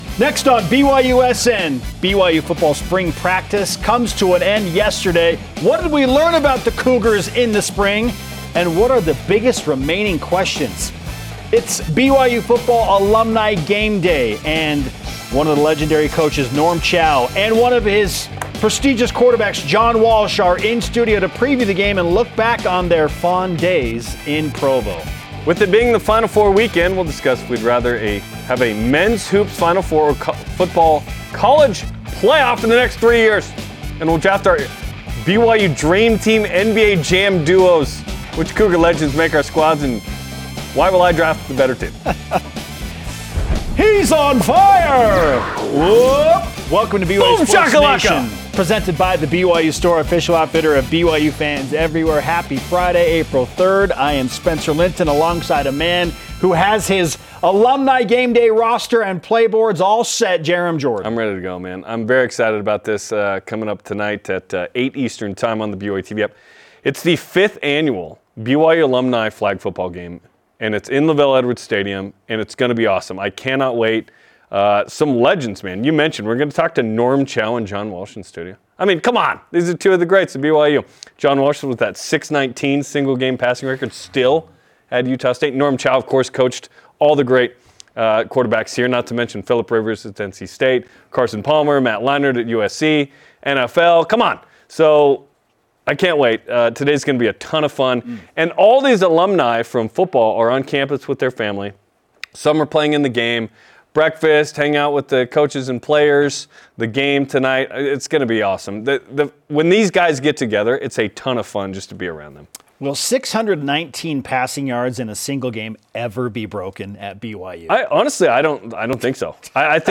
live in Studio B